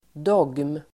Ladda ner uttalet
Uttal: [dåg:m]